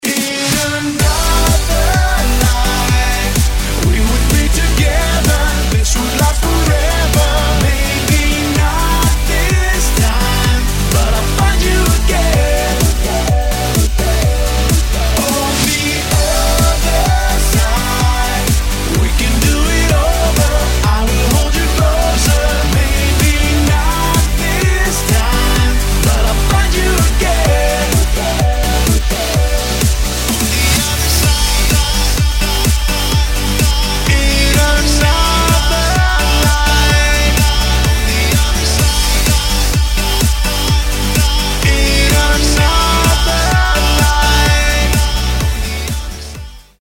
• Качество: 192, Stereo
поп